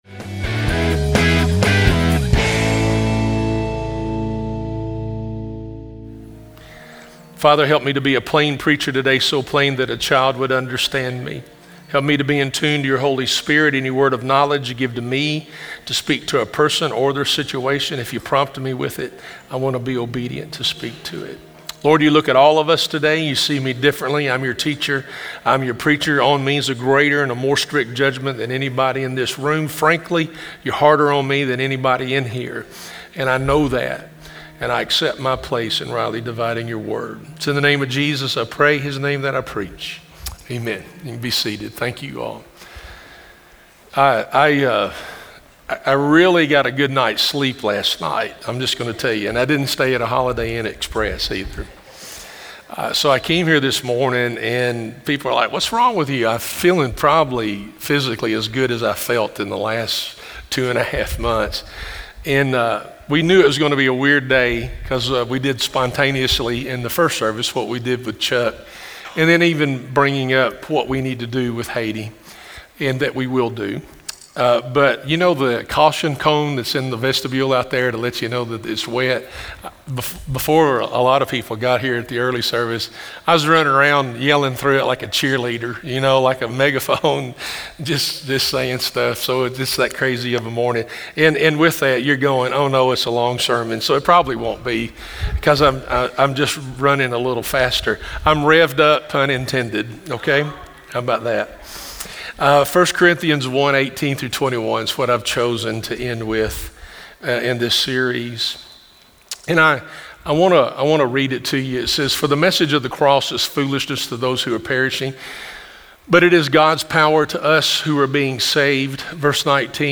Sermons | Hope Church - Tithe